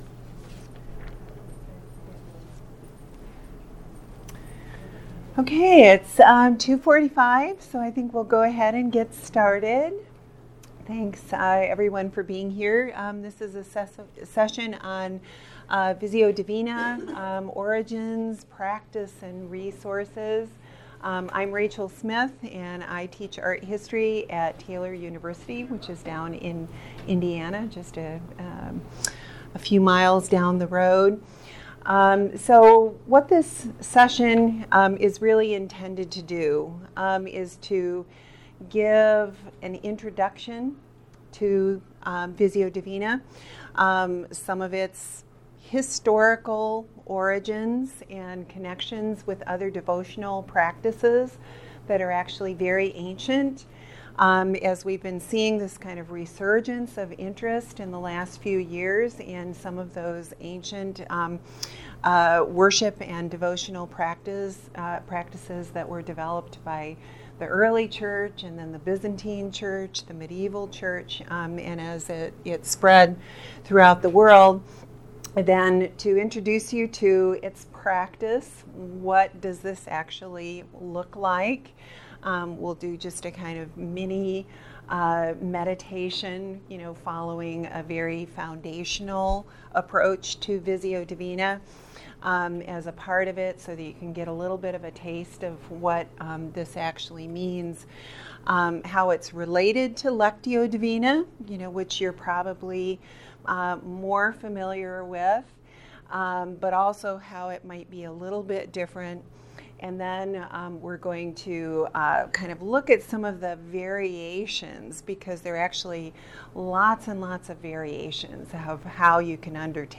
2020 Calvin Symposium on Worship | Workshop